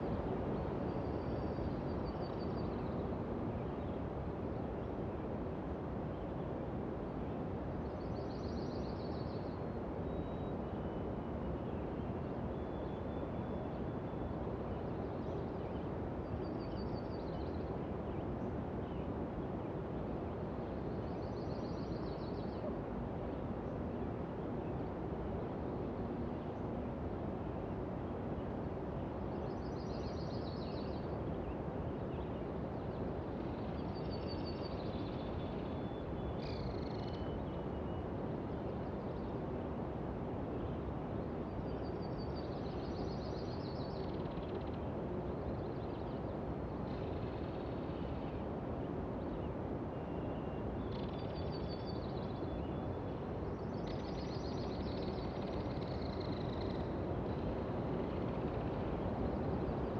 Free Fantasy SFX Pack
BGS Loops / Interior Day